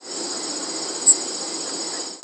Black-and-white Warbler diurnal flight calls